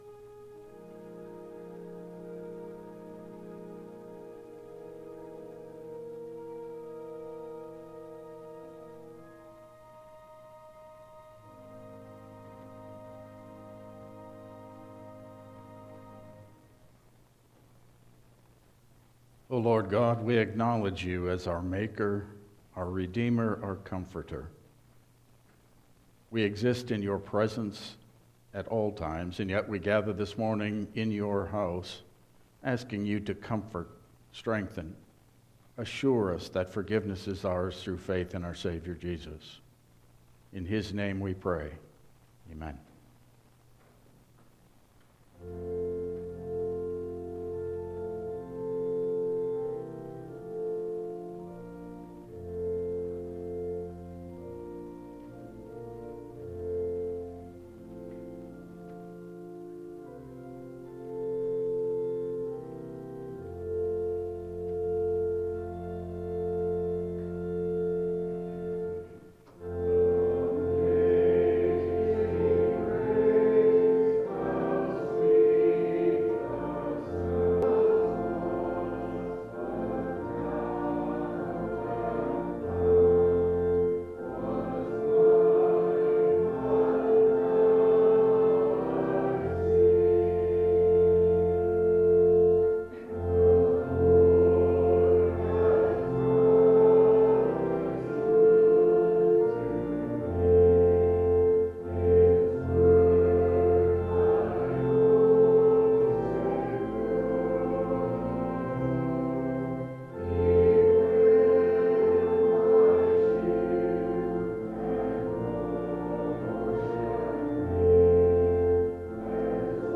Passage: Exodus 24:3-8 Service Type: Regular Service